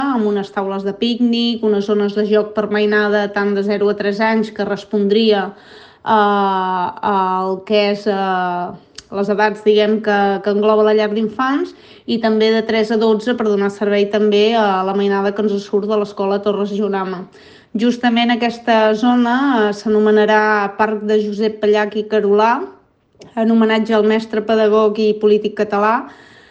Ho explica a Ràdio Capital l’alcaldessa de Mont-ras, Vanessa Peiró.